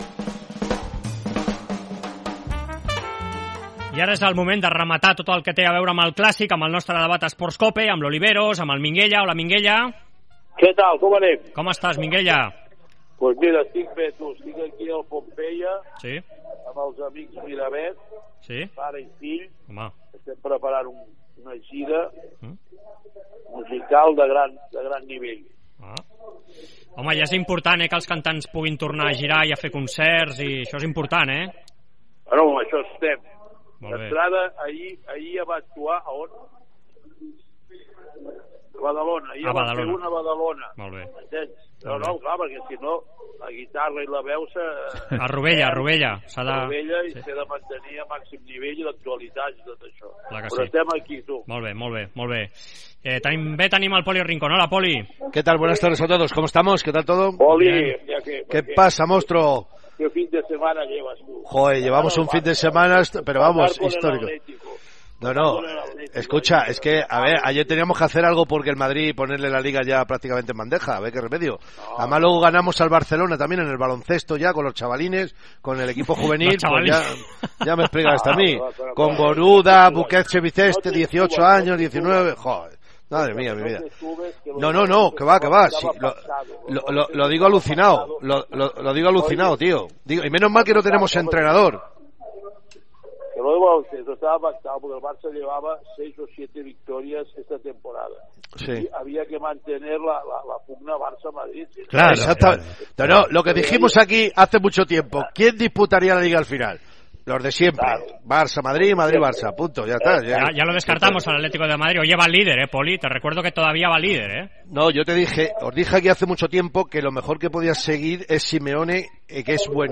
AUDIO: Escolta el 'Debat Esports COPE' amb Josep Maria Minguella i Poli Rincón analitzant les figures de Simeone, Zidane i Koeman.